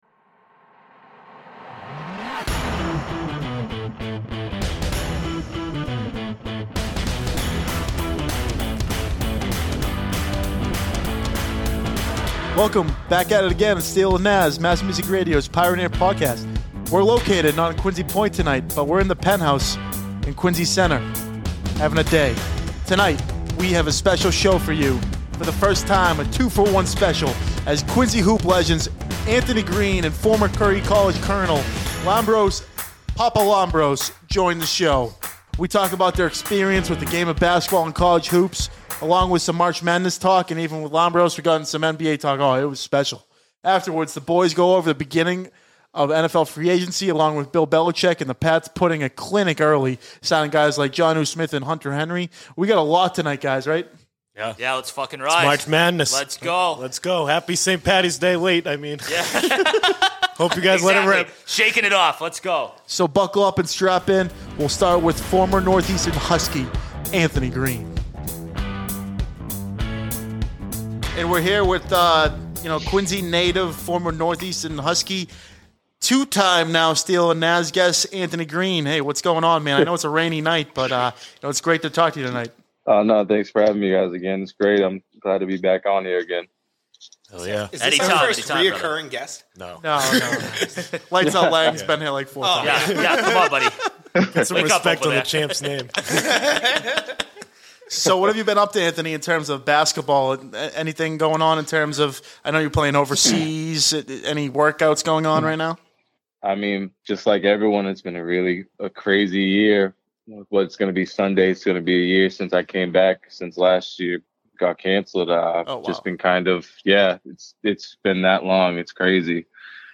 at the Mass Music Radio Station in Quincy, Massachusetts